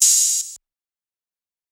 TM88 Open Hi-Hat.wav